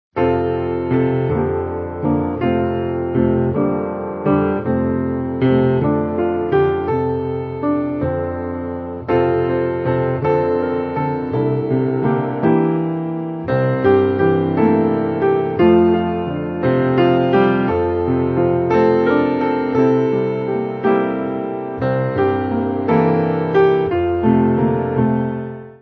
Mainly Piano
Easy Listening